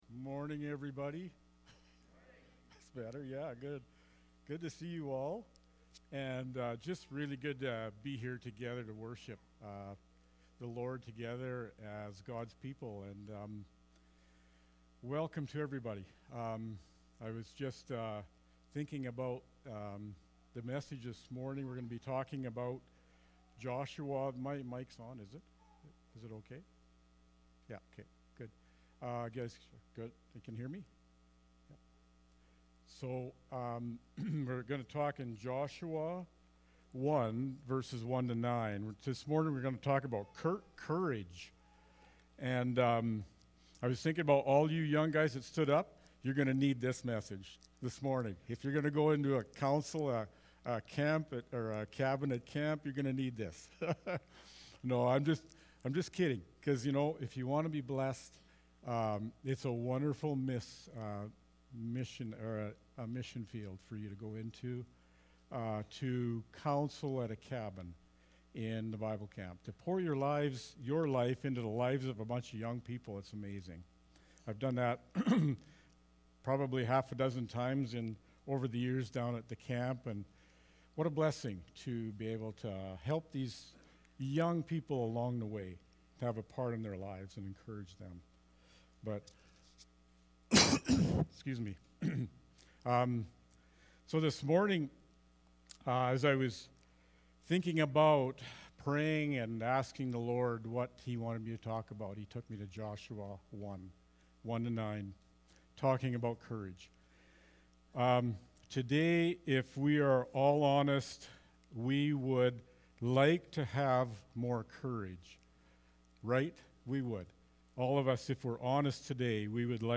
June-5-sermon-audio.mp3